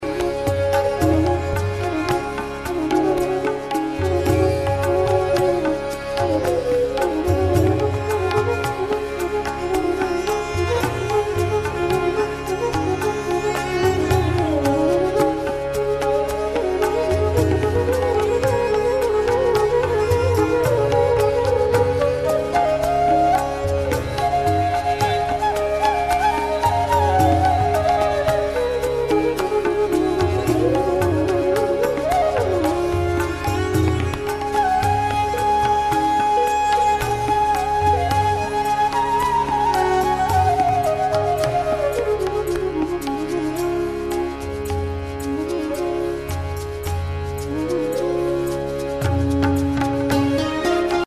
Флейта
Flute1.mp3